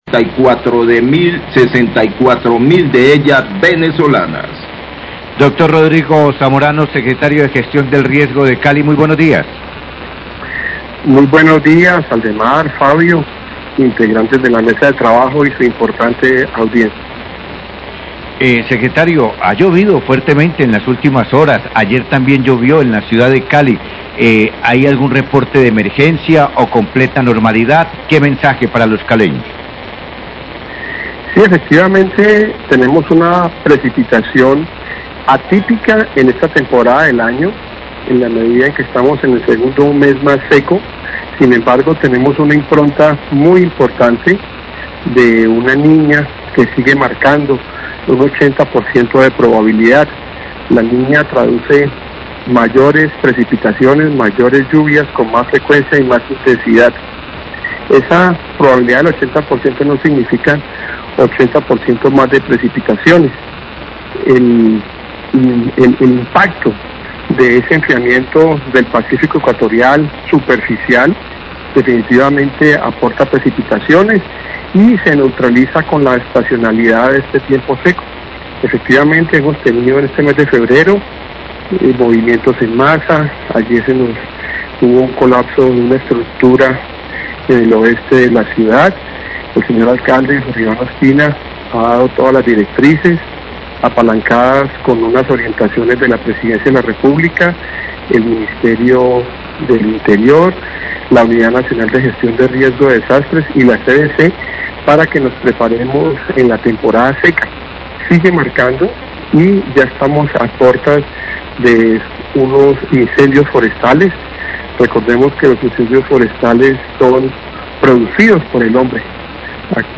Radio
Secretario de Gestión de Riesgo de Cali, Rodrigo Zamorano, se refirió a las lluvias de las últimas horas.